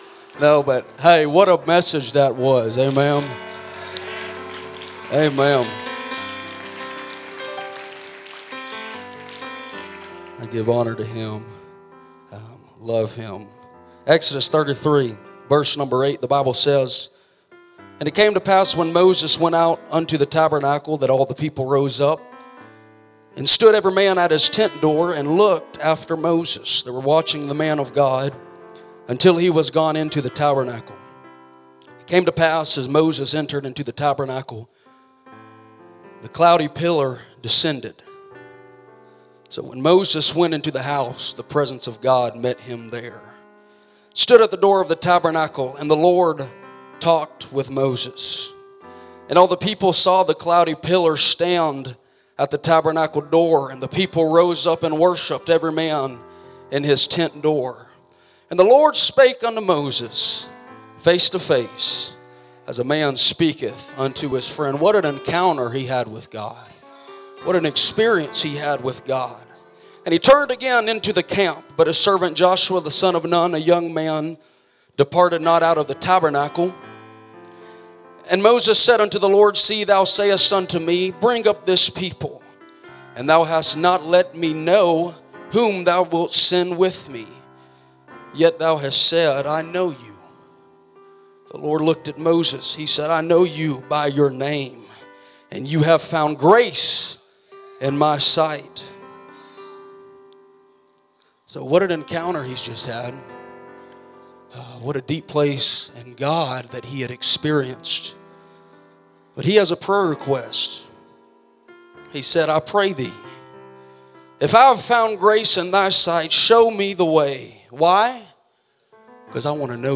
First Pentecostal Church Preaching 2022